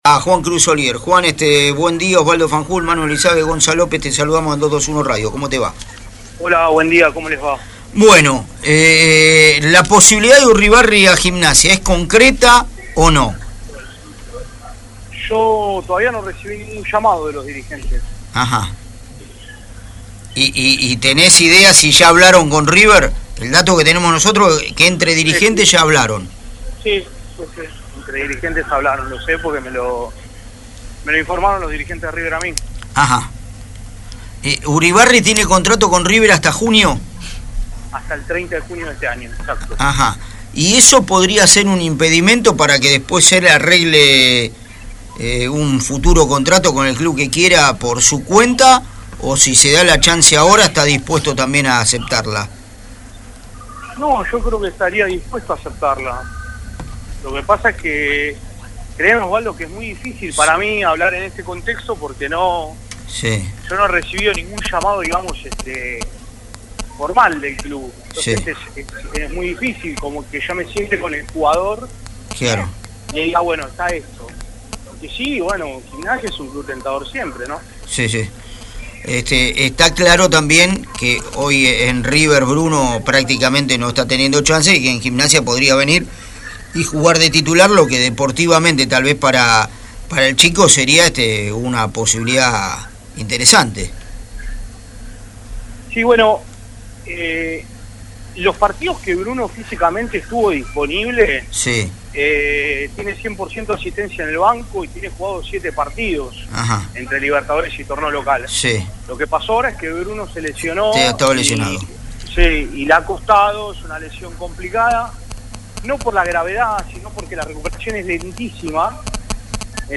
en diálogo con La Revolución del Fútbol